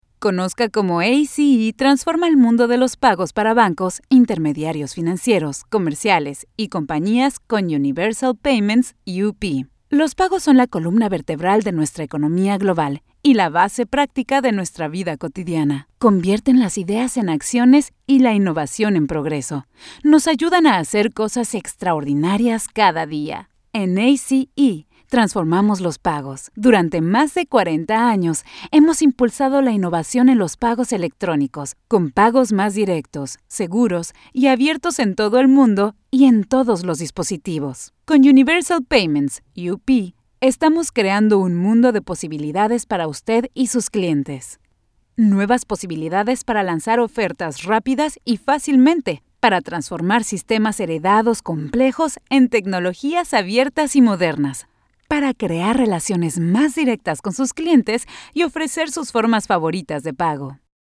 I have a clear voice and the ability to use various tones, accents, and emotions to convey material properly.
broadcast level home studio